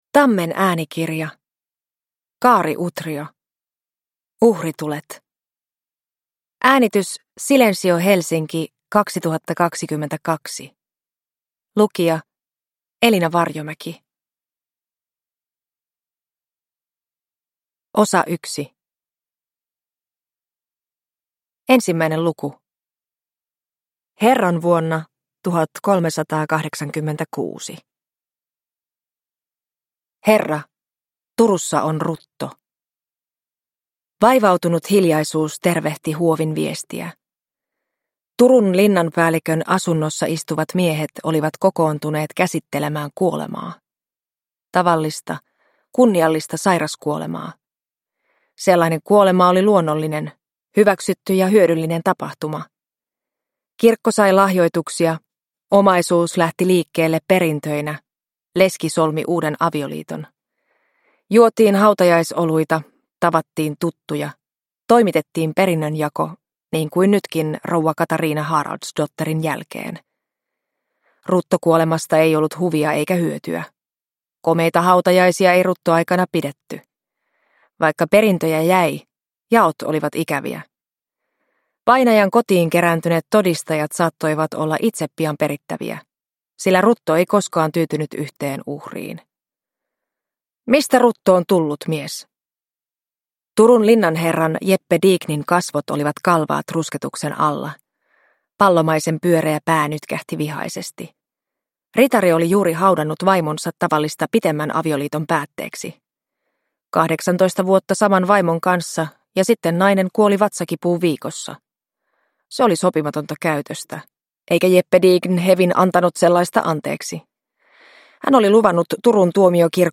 Uhritulet – Ljudbok – Laddas ner